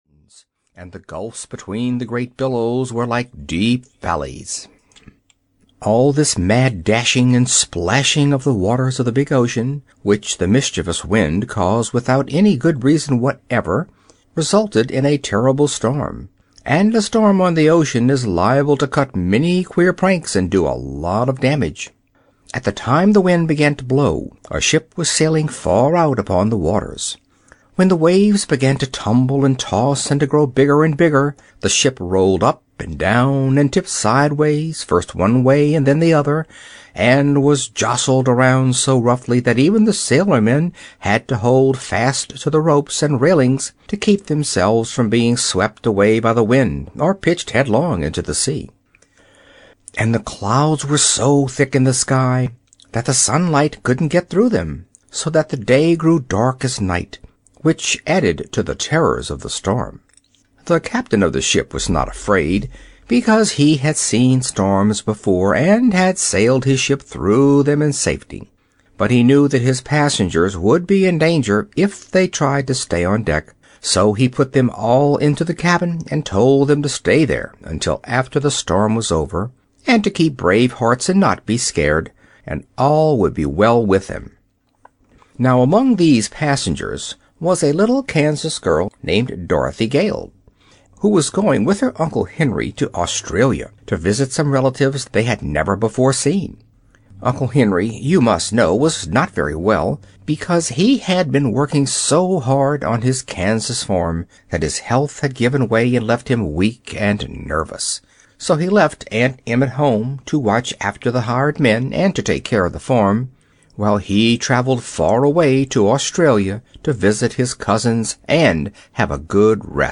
Ozma of Oz (EN) audiokniha
Ukázka z knihy